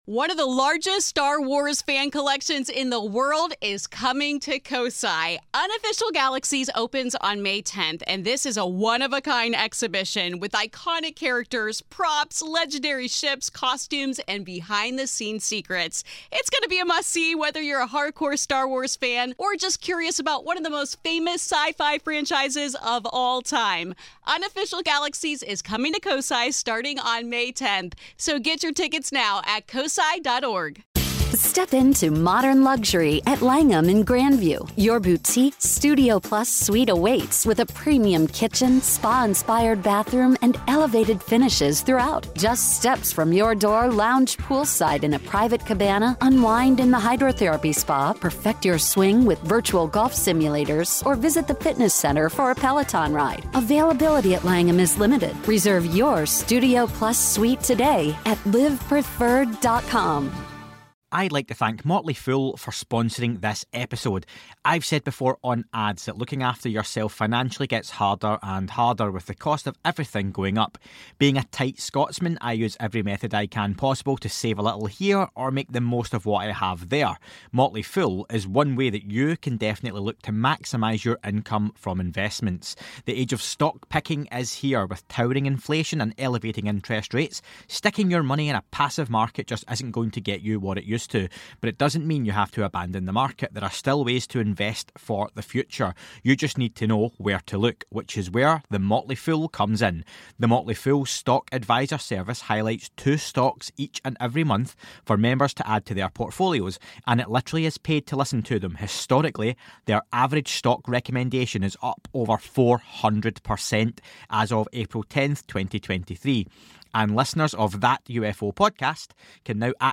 for his first podcast interview